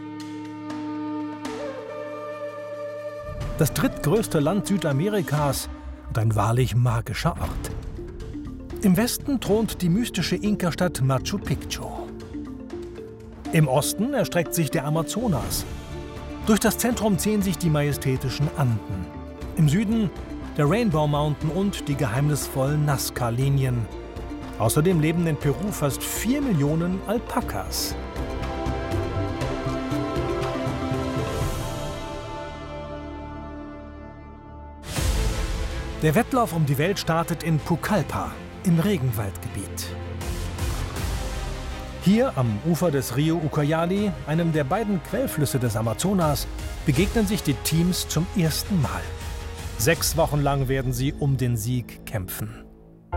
markant, sehr variabel
Mittel plus (35-65)
Comment (Kommentar)